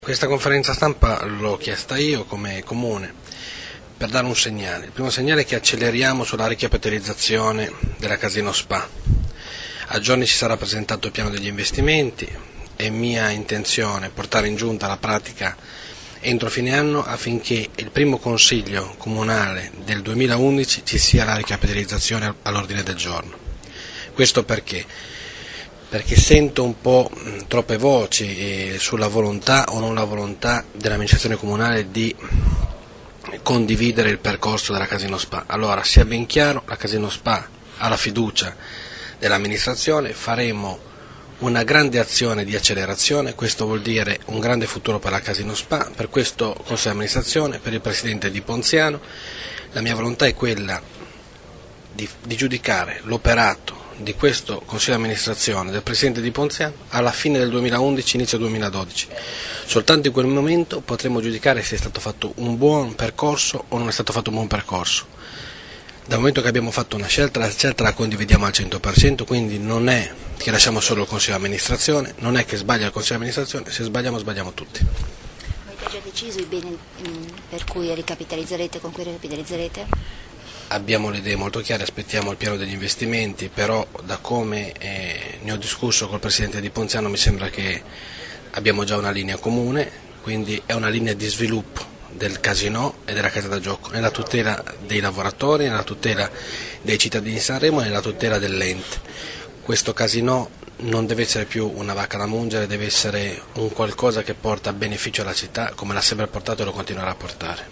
Maurizio_Zoccarato_4Dic2010.mp3